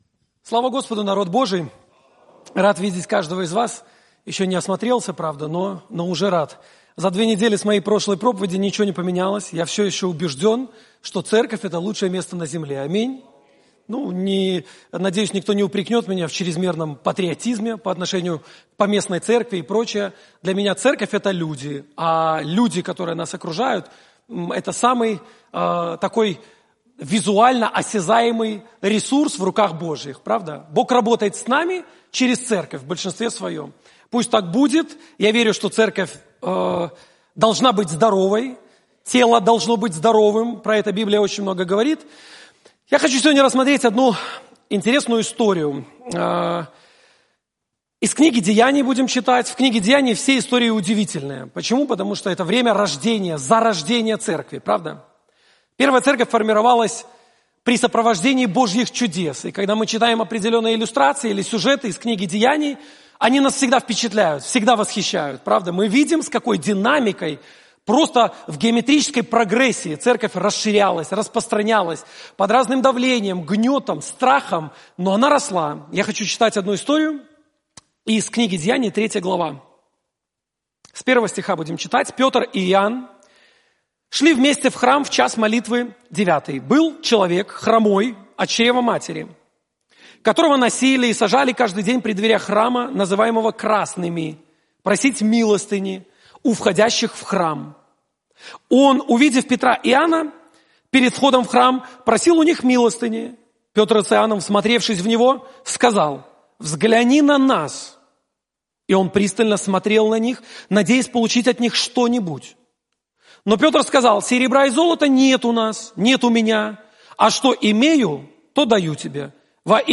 Пропаведзі